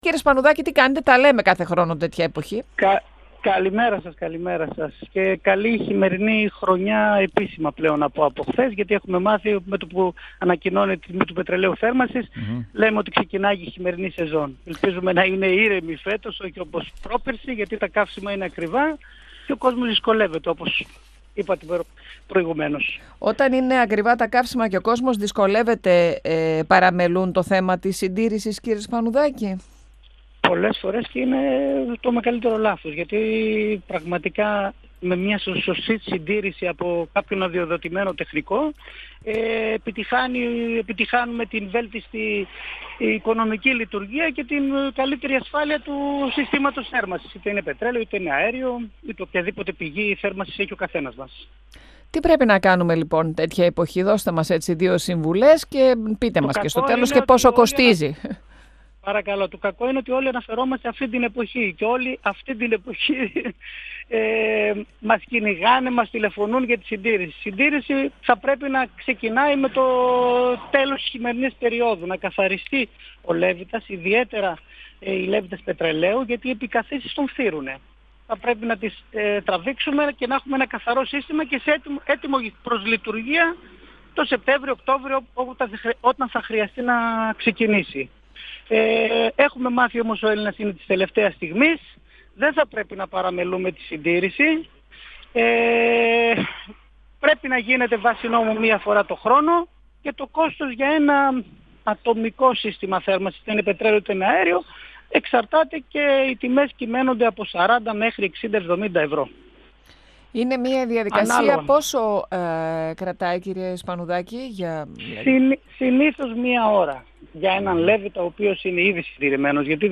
μιλώντας στον 102FM του Ραδιοφωνικού Σταθμού Μακεδονίας της ΕΡΤ3.